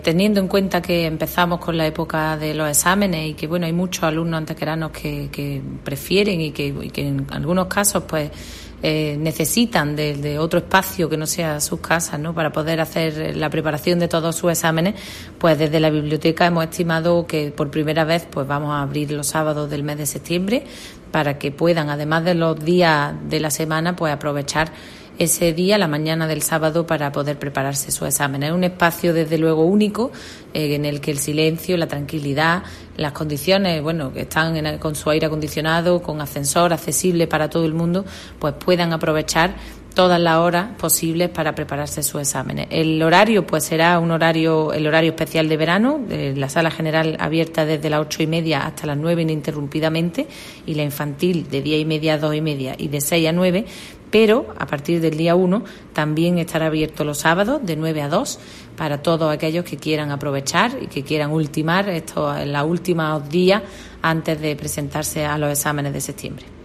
Cortes de voz A. Cebrián 456.07 kb Formato: mp3